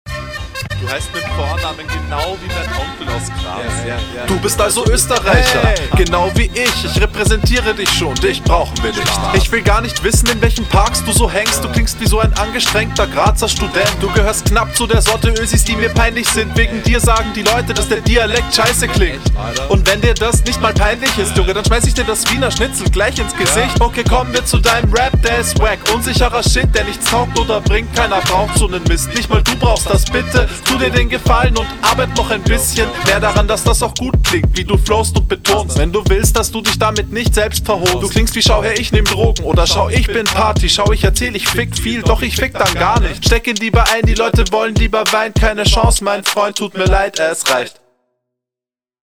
Superbeat!Der flow ist auch sehr stark gemacht hat nicht viel Variationen aber er langweilt nicht …
Der Beat ist fresh :D. Hier also auf lustig ausgelegt.